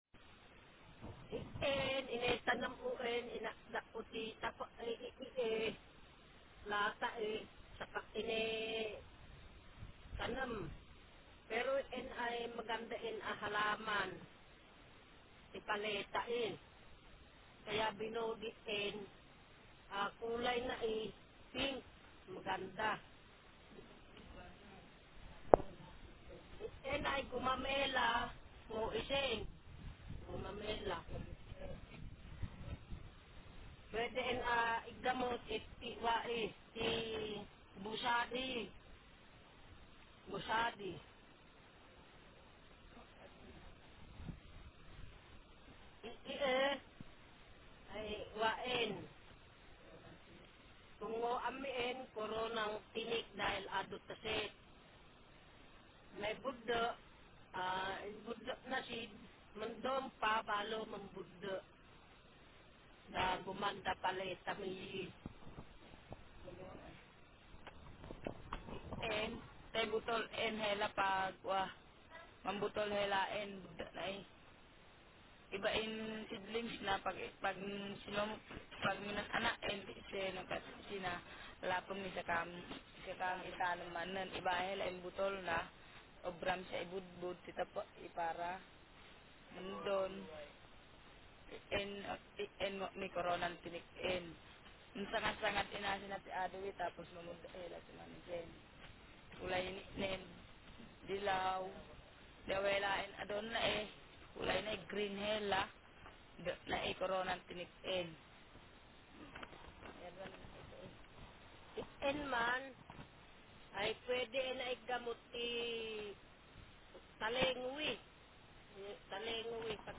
Speaker sex f Text genre procedural